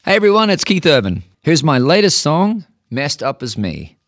LINER Keith Urban (Messed Up As Me) 4